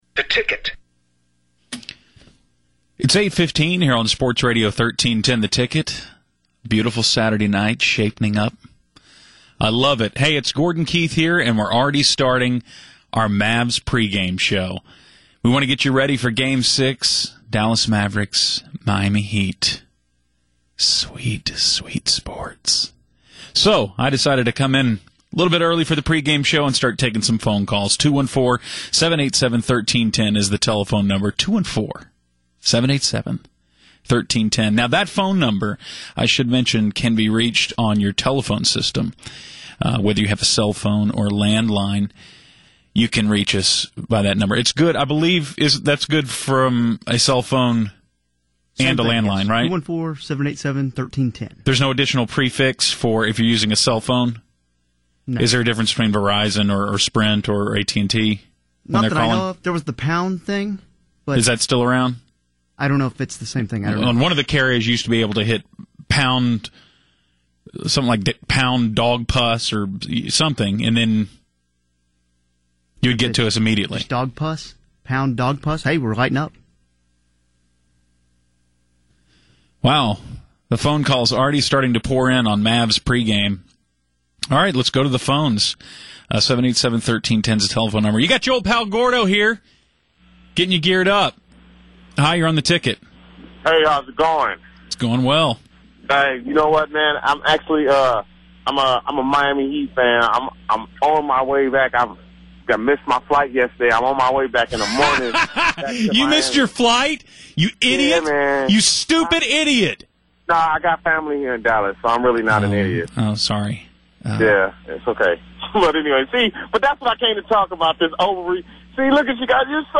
Fortunately, I’m an idiot and left my Ticket recording running, so it was saved on my hard drive.
Two hours of solid caller-driven “sports” radio.